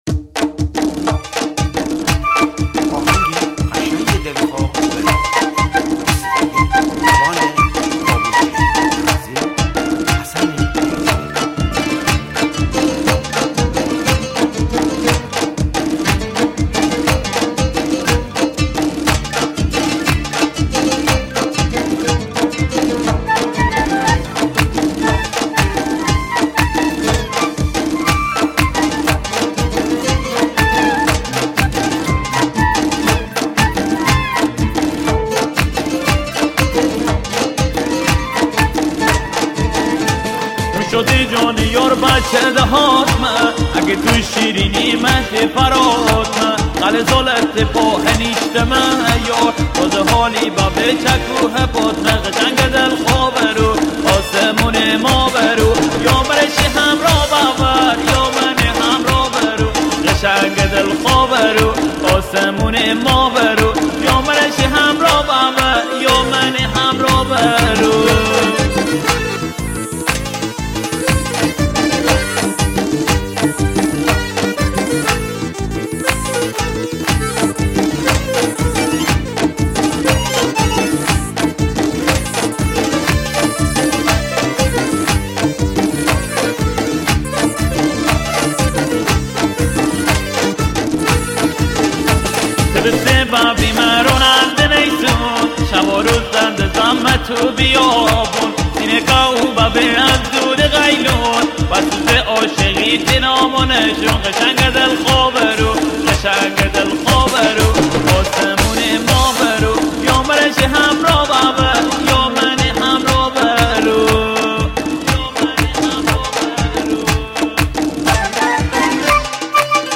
آهنگ مازندرانی
موضوع : آهنگ شاد , خوانندگان مازنی ,